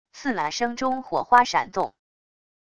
刺啦声中火花闪动wav音频